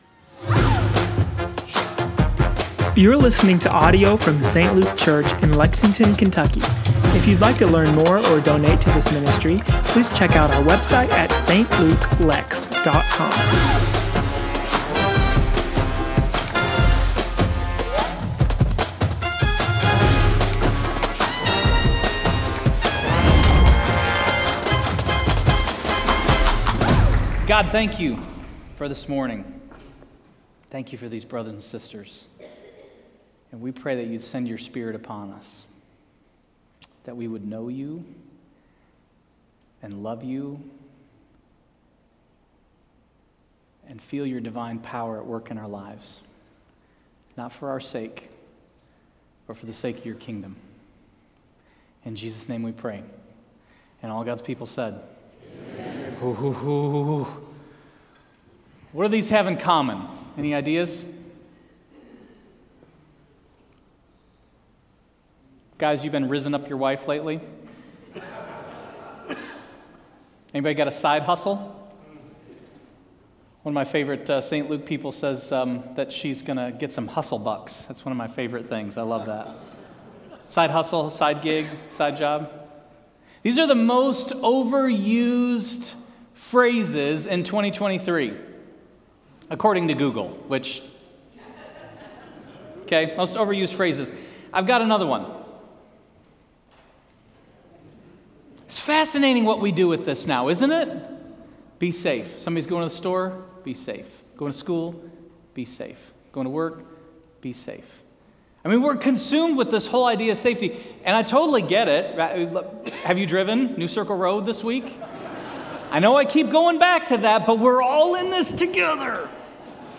Sermons & Teachings